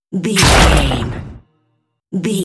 Dramatic hit bloody thunder
Sound Effects
Atonal
heavy
intense
dark
aggressive
hits